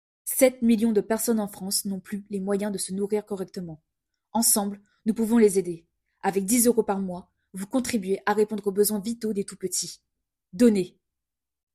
Démo Voix film associatif
10 - 30 ans - Soprano